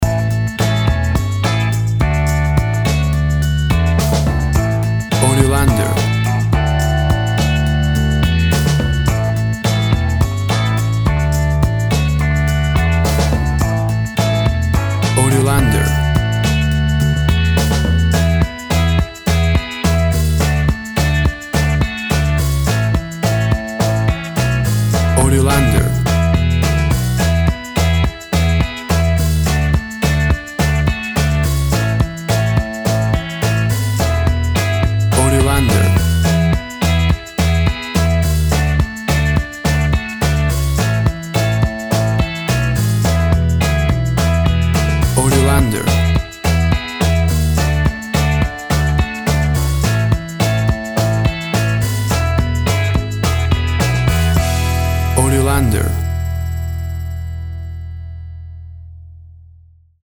WAV Sample Rate 16-Bit Stereo, 44.1 kHz
Tempo (BPM) 105